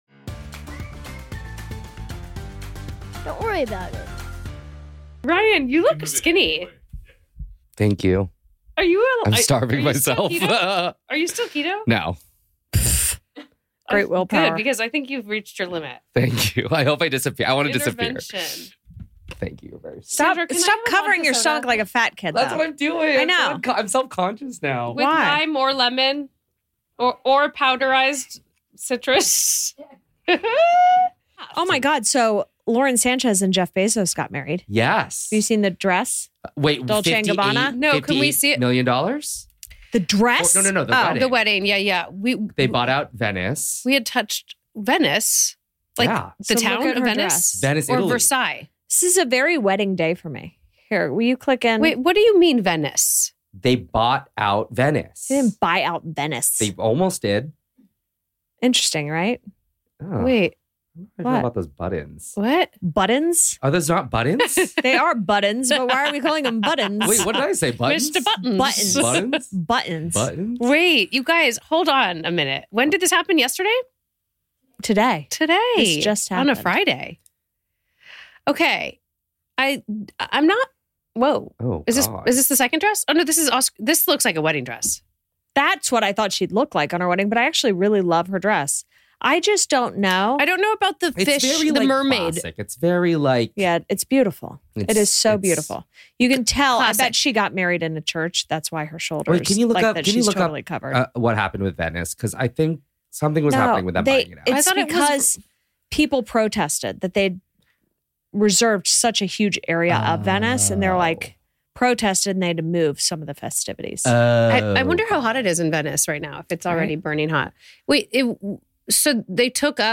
The trio is back for another funny one.